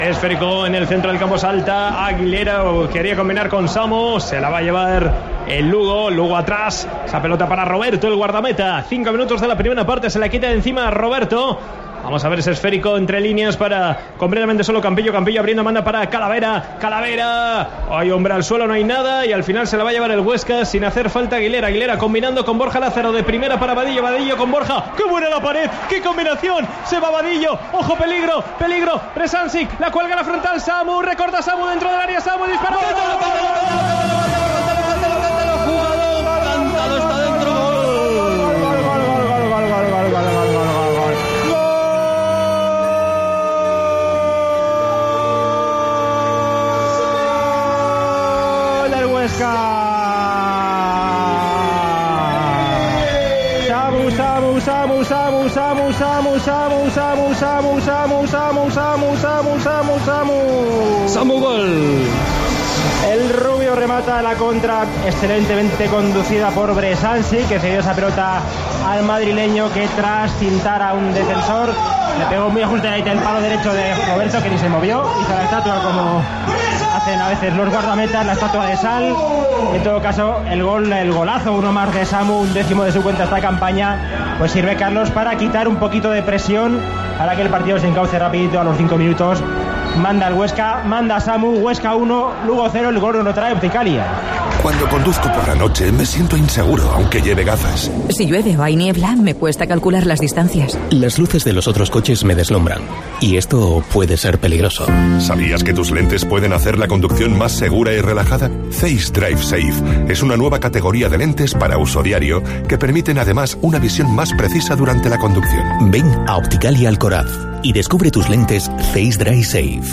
Así cantamos el gol de Samu Sáiz al Lugo